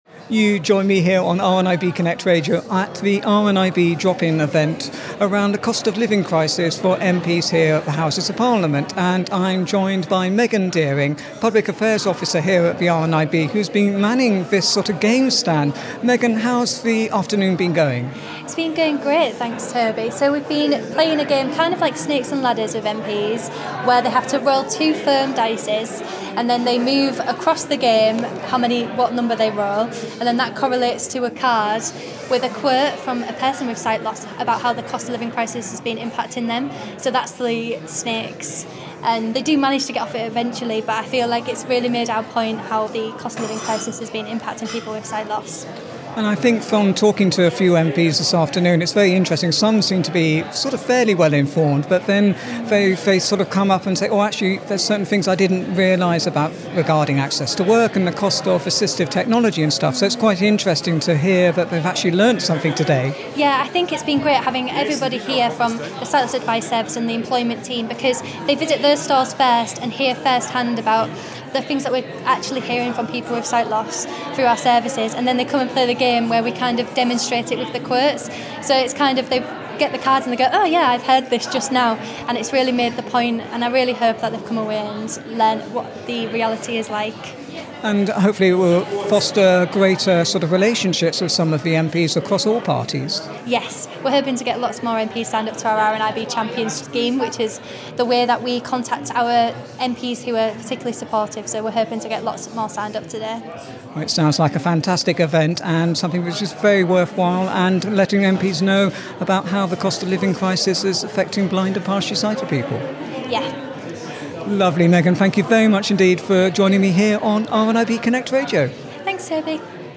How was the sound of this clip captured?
On Monday 24 April 2023 the RNIB held a MP drop-in event at the Houses of Parliament with an interactive game, a quiz and manned stalls to highlight and make MPs more aware of how the Cost of Living crisis is impacting on the lives of blind and partially sighted people.